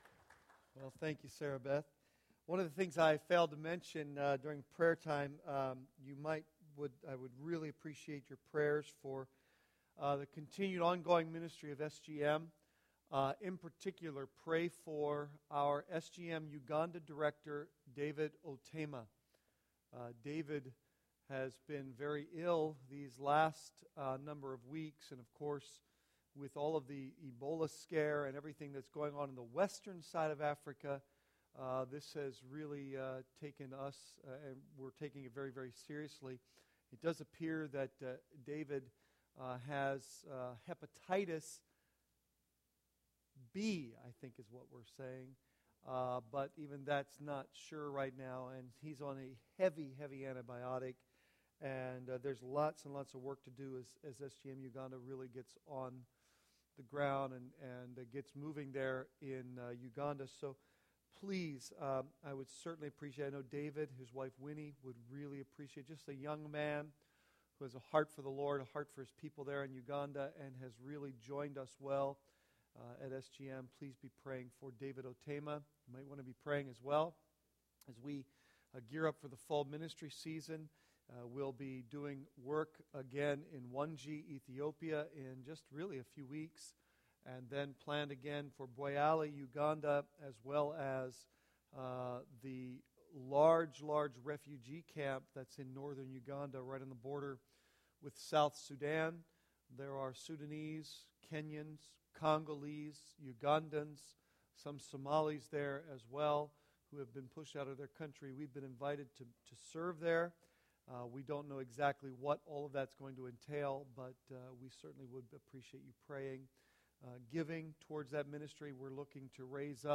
Sermons Archive - Page 67 of 90 - Calvary Bible Church - Wrightsville, PA